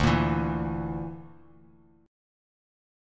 Dsus2#5 chord